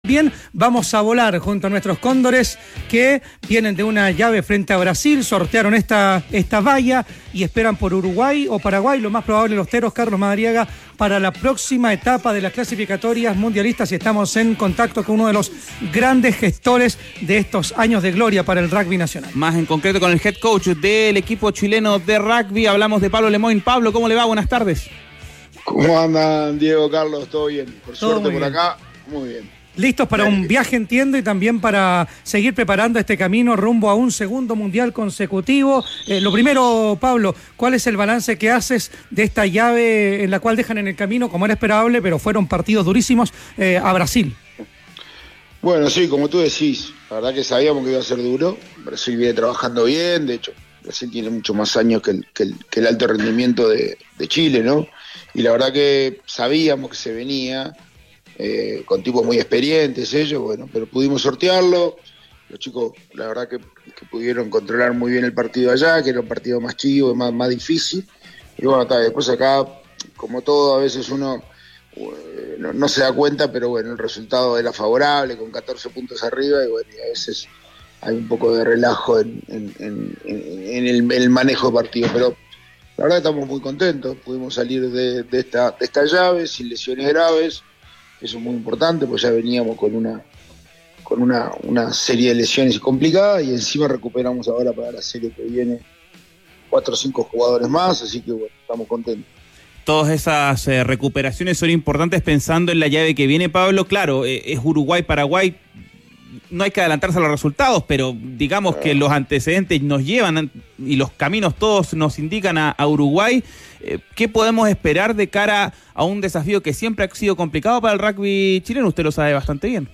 En conversación con ADN TOP, el head coach de los Cóndores anticipó el inminente cruce con los “Teros”.